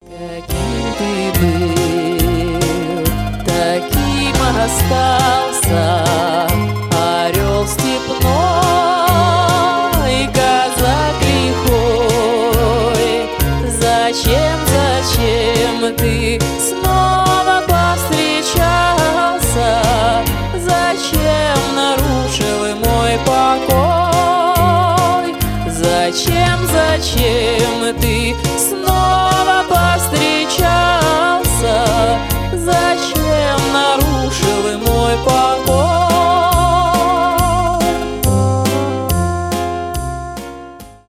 cover , застольные , ретро